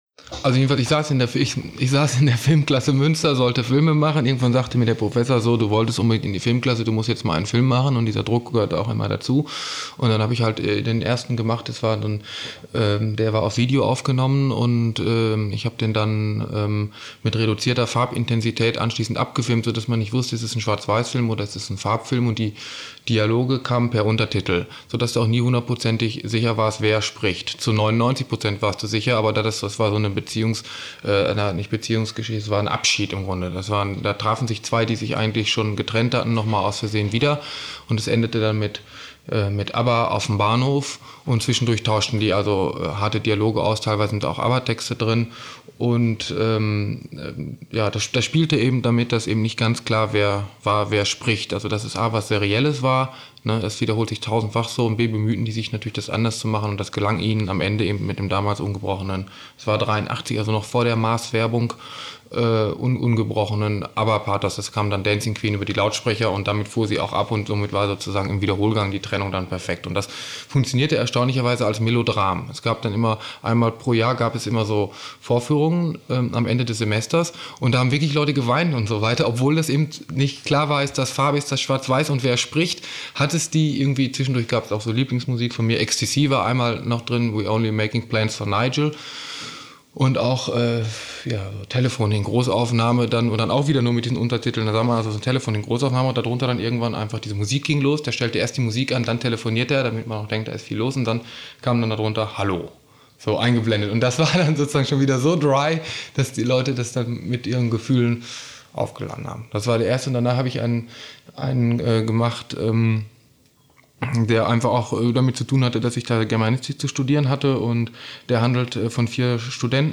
55 Min. ungeschnitten